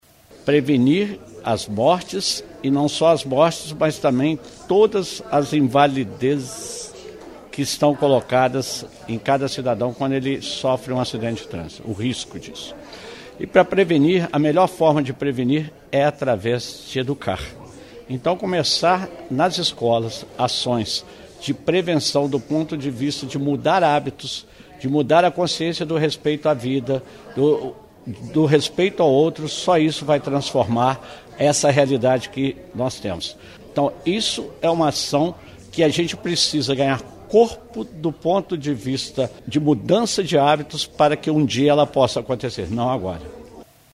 Para o prefeito Antônio Almas, o projeto tem a importância de prevenir e educar.